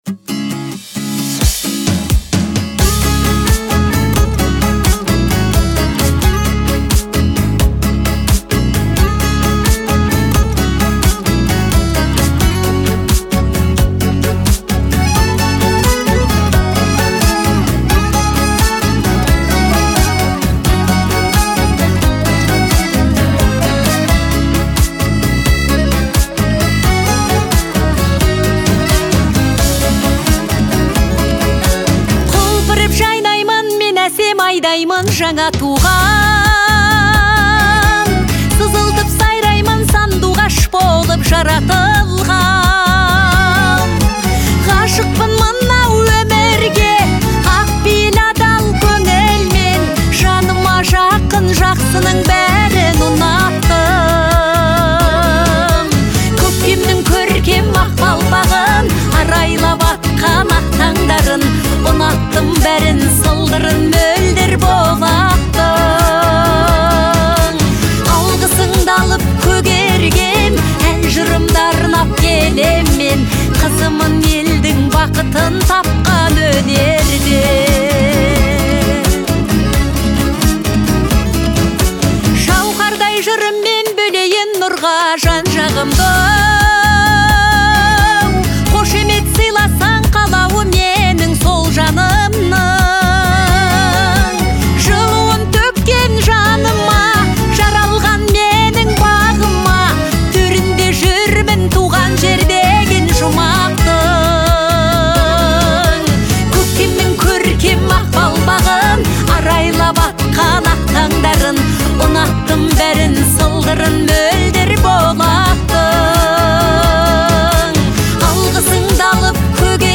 Жанр композиции можно отнести к народной и поп-музыке.